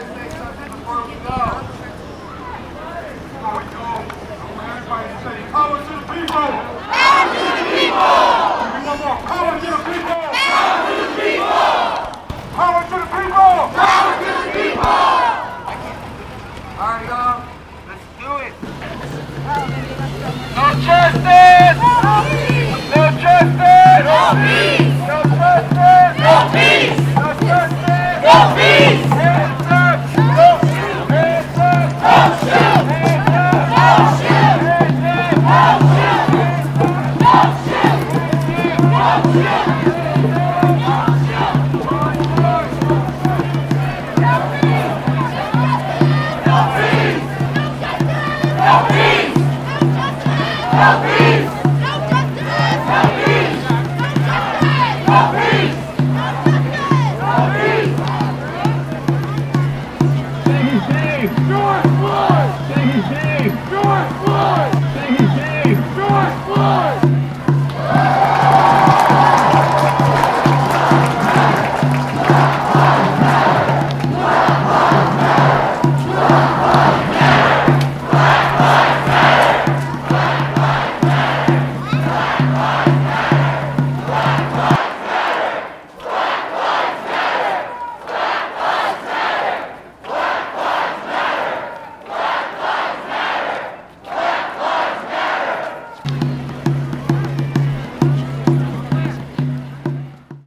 Cries of “No justice, no peace” and “Say his name: George Floyd!” are heard on this mashup of voices from a completely peaceful protest in Mesa, Arizona, gathered by sound artist